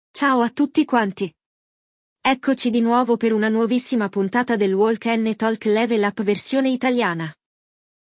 Neste episódio, vamos ouvir o diálogo entre Roberto e Mariano, dois senhores que conversam sobre alguns problemas de conexão que Mariano teve enquanto assistia a partida de futebol da noite passada.